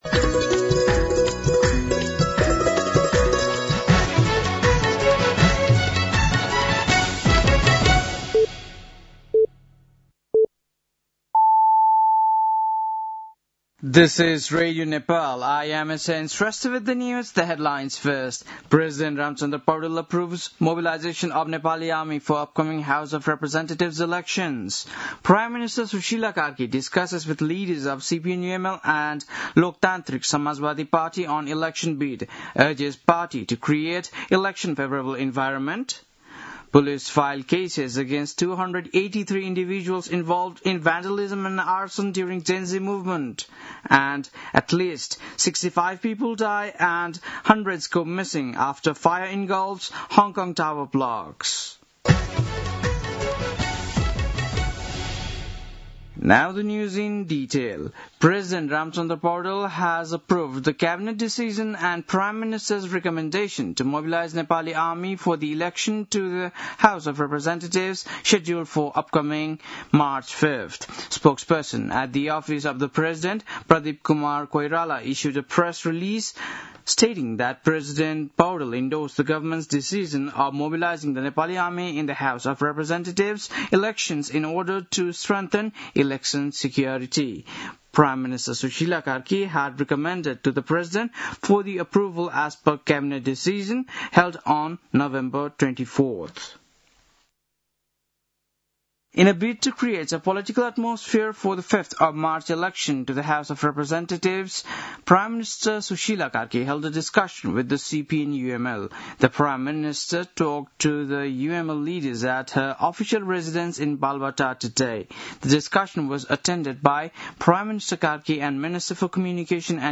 बेलुकी ८ बजेको अङ्ग्रेजी समाचार : ११ मंसिर , २०८२
8-pm-news-8-11.mp3